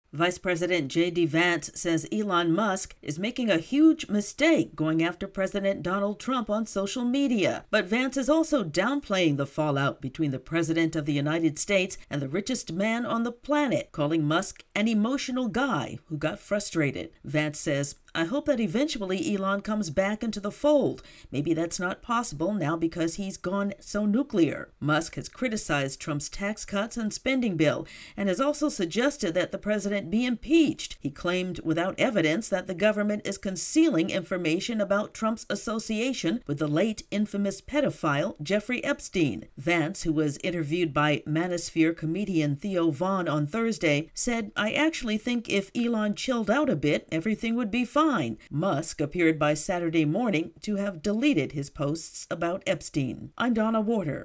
Vice President JD Vance is talking about the disintergrating relationship between President Donald Trump and Elon Musk.